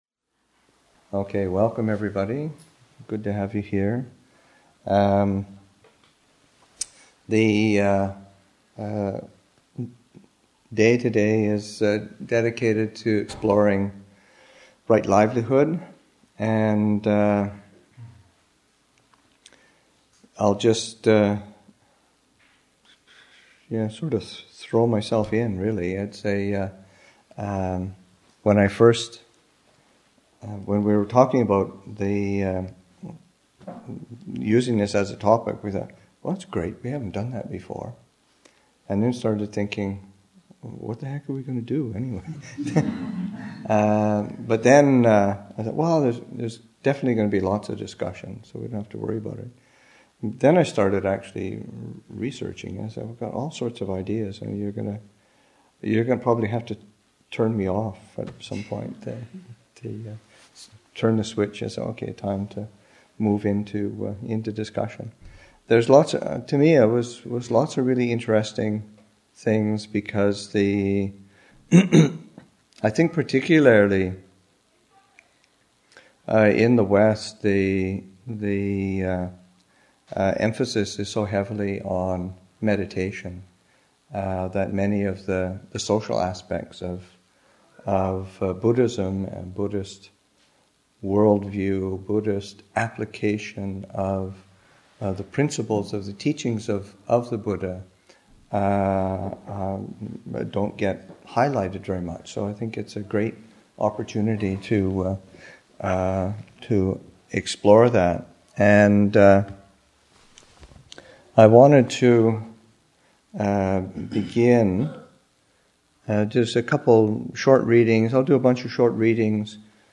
[2:22] Readings: Buddhist Economics: A Middle Way for the Market Place by P. A. Payutto, p. 19; Buddhist Economics: A Middle Way for the Market Place by P. A. Payutto, p. 36.